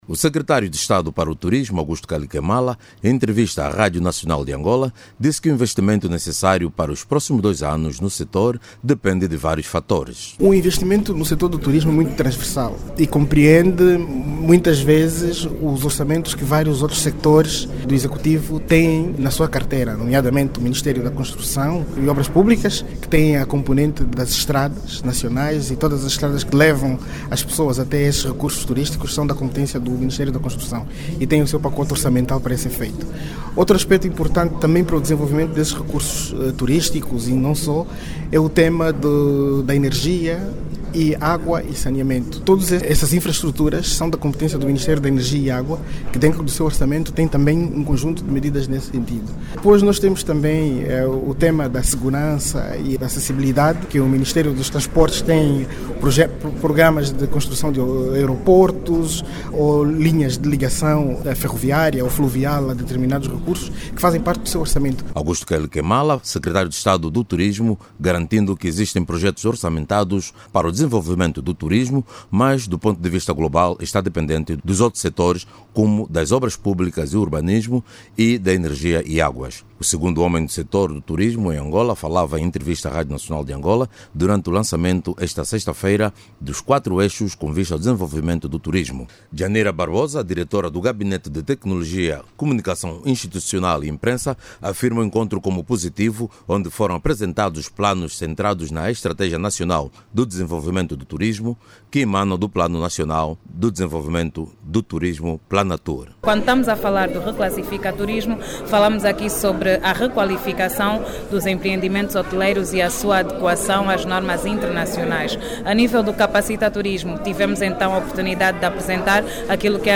Trata-se do Reclassificação do Turismo, Comunica Turismo, Planifica Turismo e o Capacita Turismo são os quatro eixos que sustentam o projecto do executivo para o turismo em Angola. Saiba mais dados no áudio abaixo com o repórter